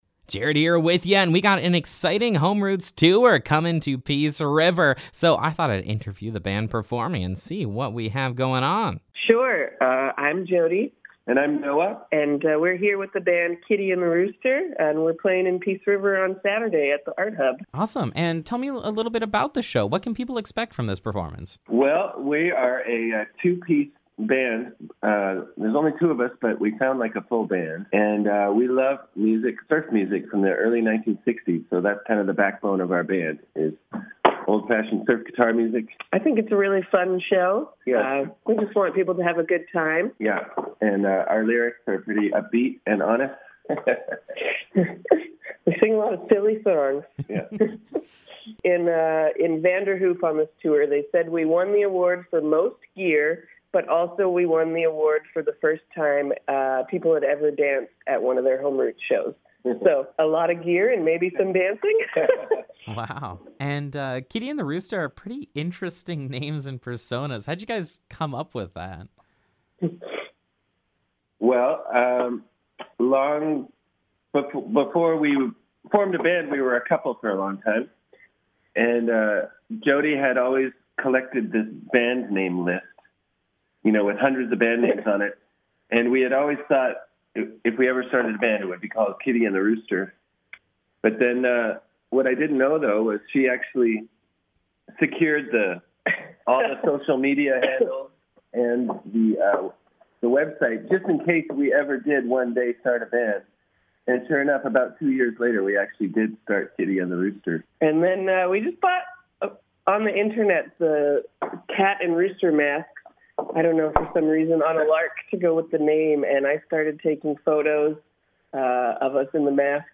Kitty & The Rooster Interview
kitty-rooster-interview.mp3